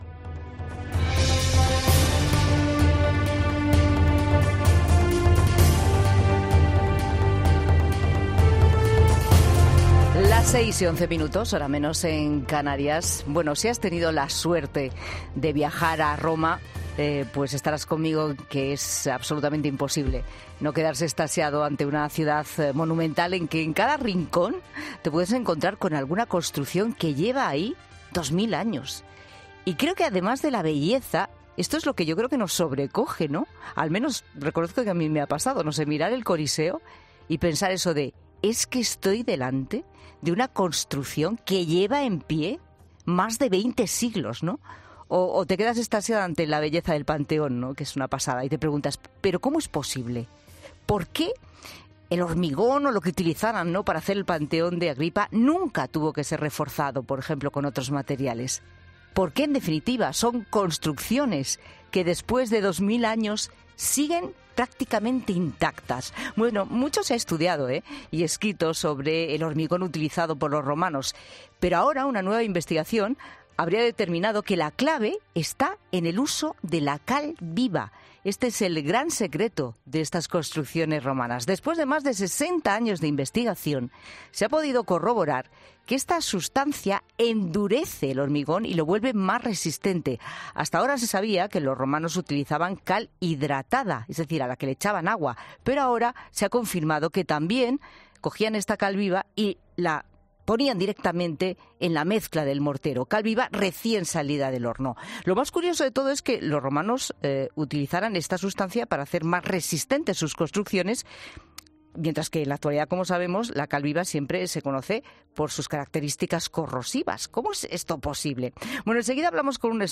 La empresa de biotecnología Colossal asegura que planean revivir a la especie de mamuts para 2027 y, en La Tarde de COPE, el divulgador científico Jorge Alcalde ha analizado las consecuencias que tendría para el planeta, tanto devolver a la vida al lanudo como volver a incluirlo a gran escala en el ecosistema de todo el planeta.